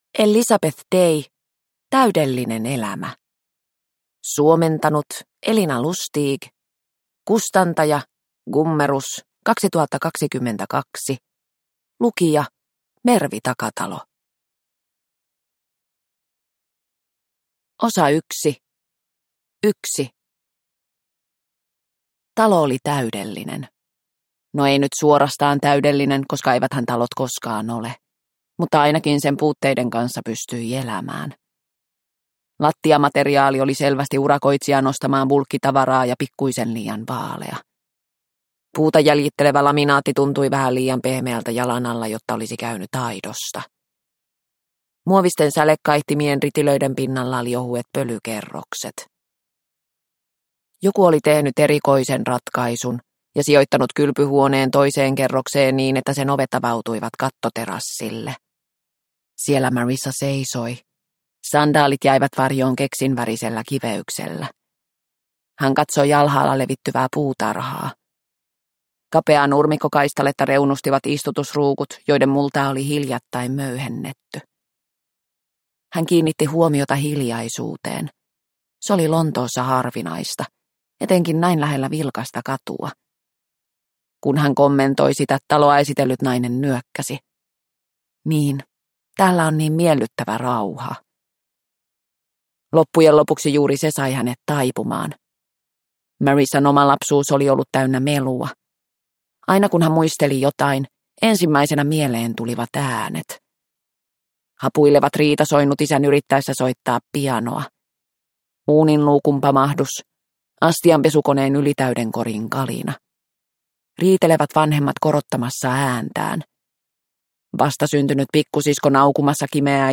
Täydellinen elämä – Ljudbok – Laddas ner